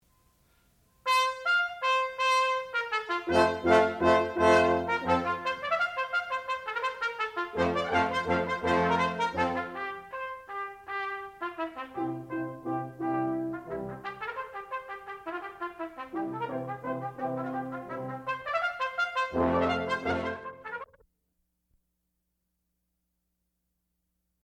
sound recording-musical
classical music
trumpet
french horn